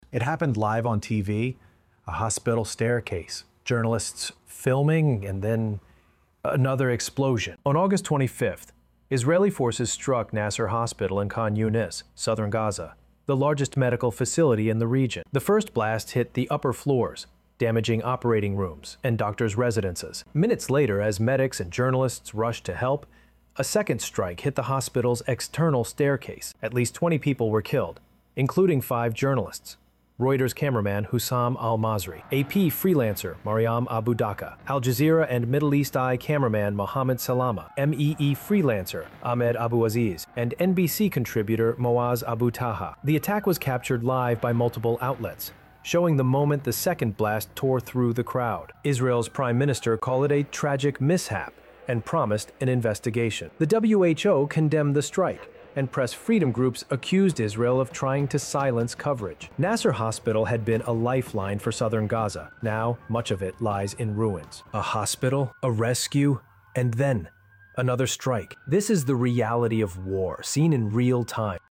🚨😥Live TV captured the moment a second strike hit Nasser Hospital in southern Gaza — minutes after the first blast. At least 20 killed, including five journalists, medics, and patients. The WHO calls it a violation of humanitarian law.